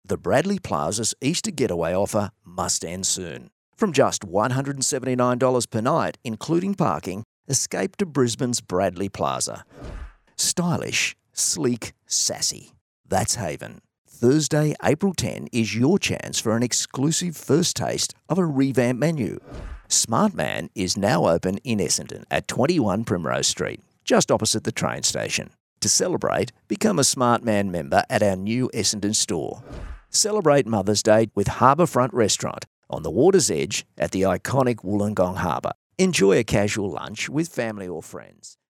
But pretty much specialises in the laid back Aussie style, relaxed , real and believable!
• Soft Sell
• Natural Aussie Bloke